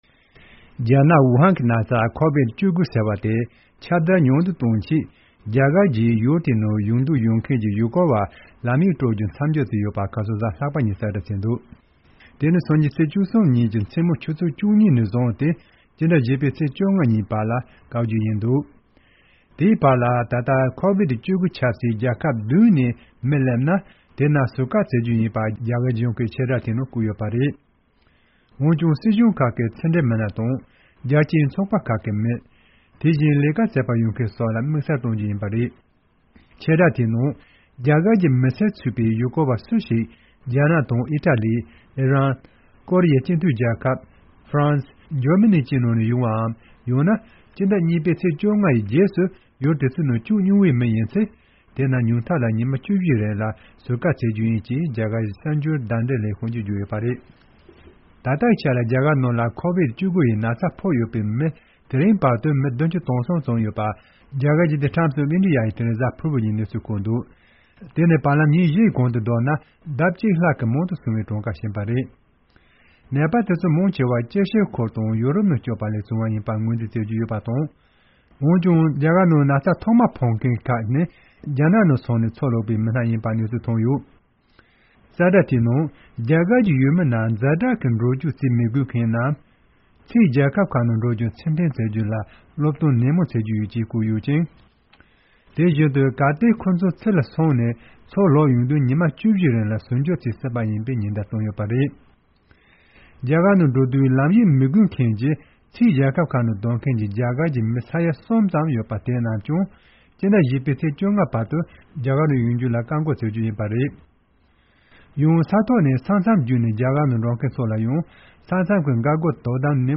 ཕྱོགས་བསྒྲིགས་དང་སྙན་སྒྲོན་ཞུ་གནང་གི་རེད།